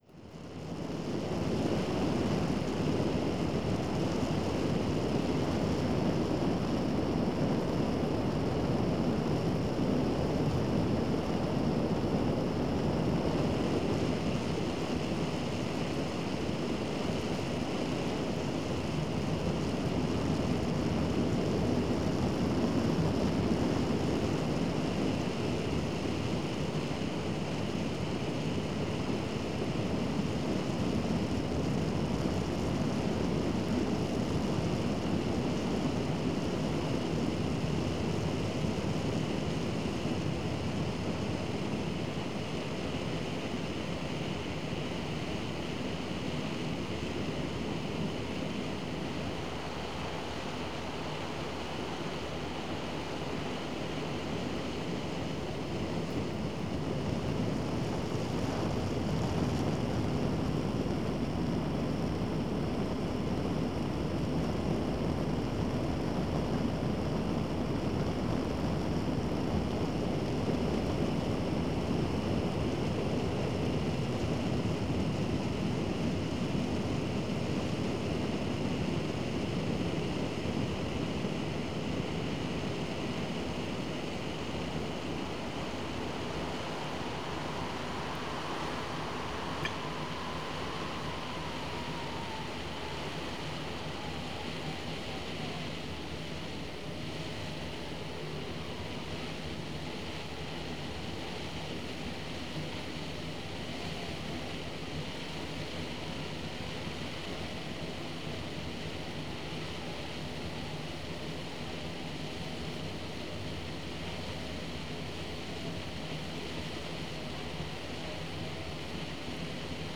This is a compilation from the ongoing project ‘Situations and Circumstances’ as a series of field recordings that are made public in this release intending to be available for free download and potential reuse in new works of other artists.